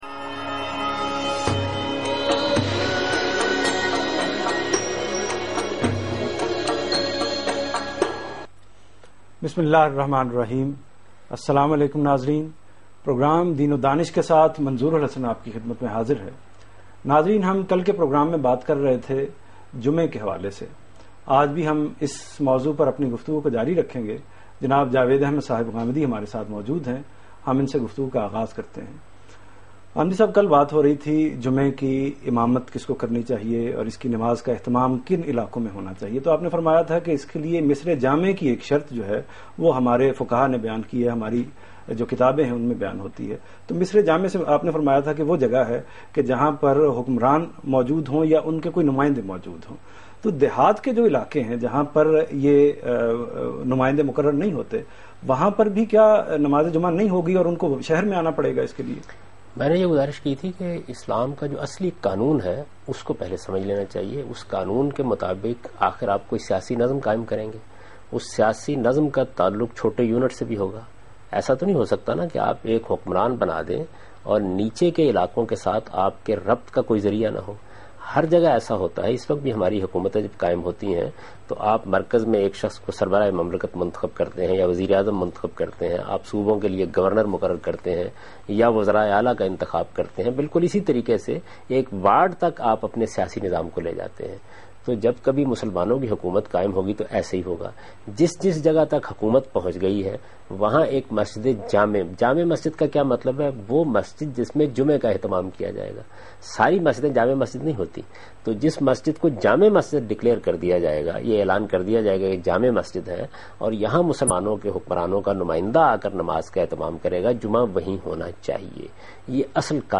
TV Programs
Javed Ahmed Ghamidi in Aaj TV Program Deen o Danish Ramzan Special.
جاوید احمد غامدی آج ٹی وی کے پروگرام دین ودانش میں رمضان کے متعلق گفتگو کر رہے ہیں